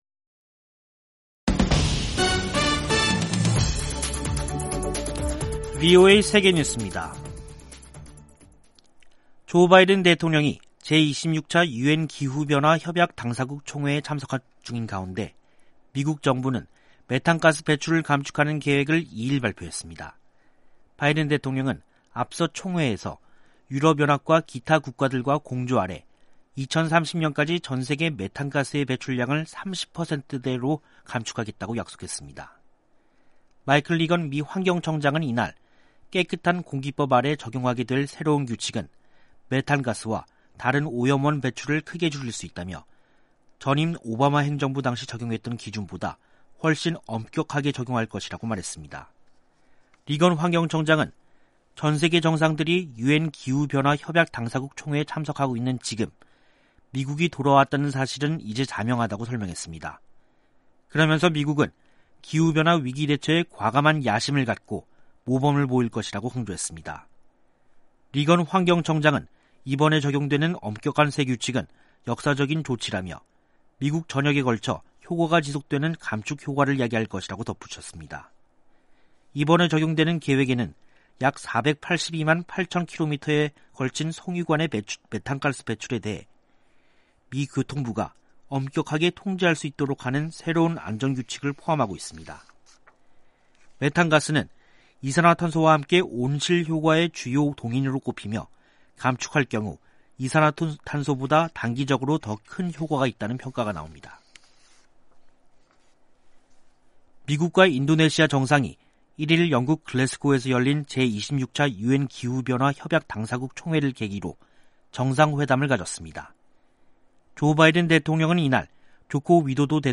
세계 뉴스와 함께 미국의 모든 것을 소개하는 '생방송 여기는 워싱턴입니다', 2021년 11월 2일 저녁 방송입니다. '지구촌 오늘'에서는 제 26차 유엔기후변화협약 당사국 총회(COP26) 진행 상황 짚어보고, '아메리카 나우'에서는 연방 대법원이 텍사스주 임신 중절 제한 위헌 소송에 관한 변론을 들은 이야기 전해드립니다.